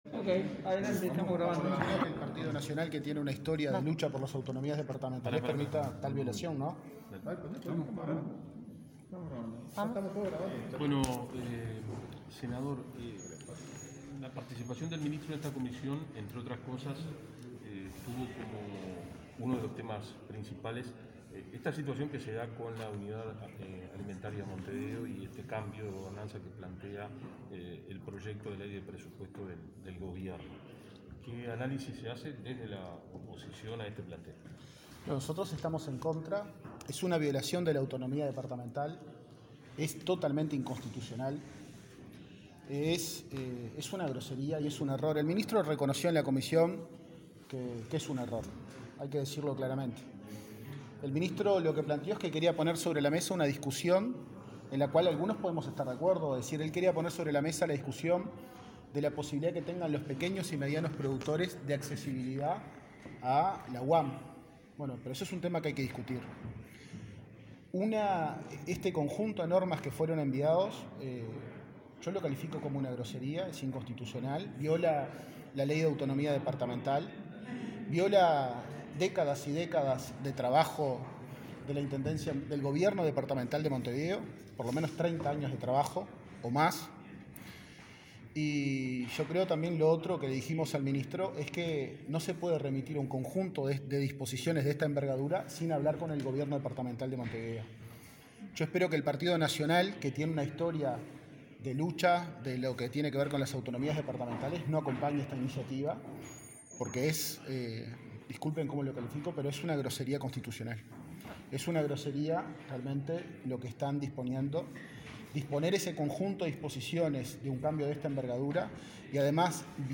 Dejamos disponible el audio completo de las declaraciones.